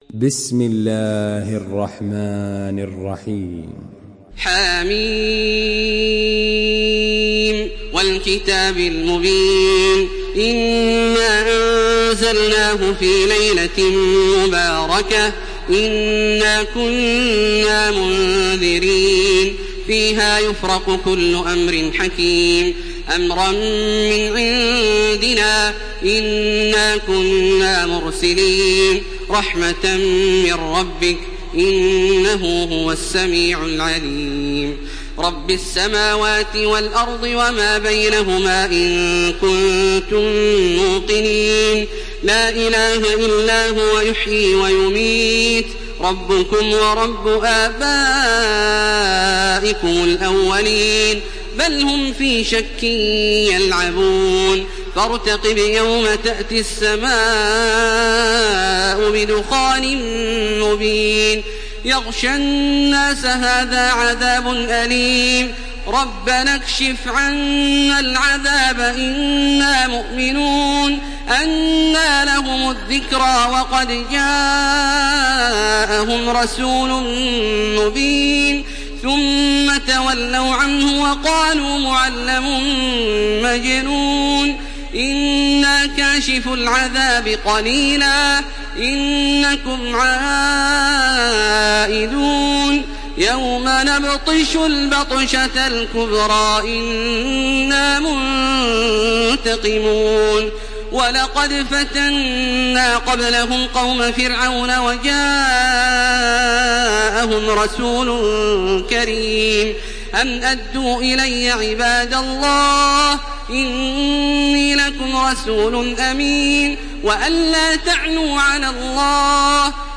Surah Ad-Dukhan MP3 in the Voice of Makkah Taraweeh 1429 in Hafs Narration
Murattal Hafs An Asim